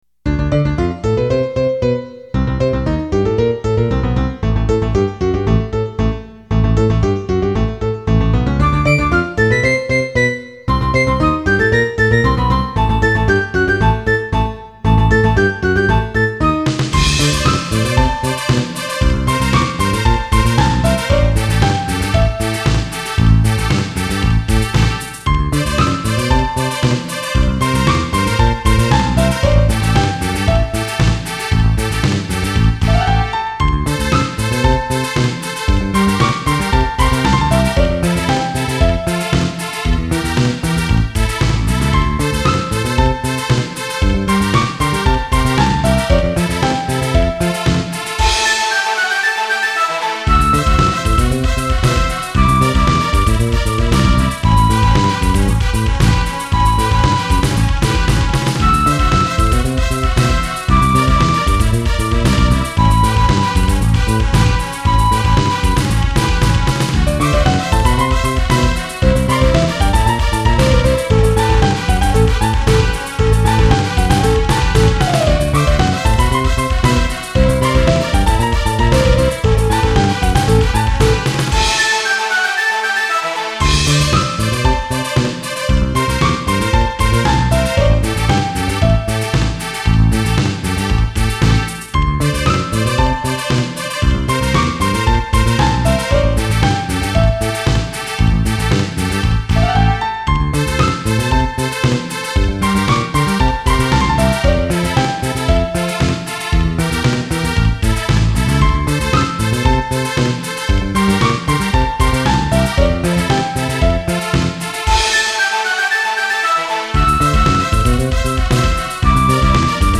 SC88Pro